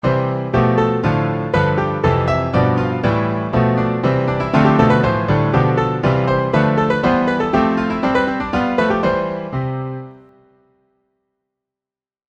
Textura homofónica. Ejemplo.
tríada
piano
mayor